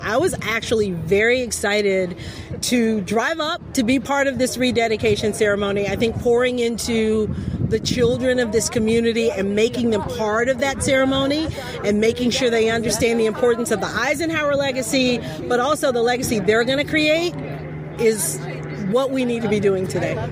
Yesterday, Eisenhower Elementary School was officially recommissioned with ceremonies held at the school’s multi-purpose room.